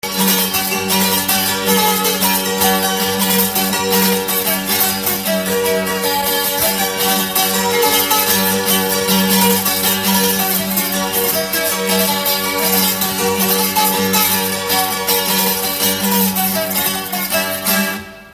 Dunántúl - Somogy vm. - Kadarkút
citera
Műfaj: Ugrós
Stílus: 7. Régies kisambitusú dallamok
Kadencia: b3 (1) b3 1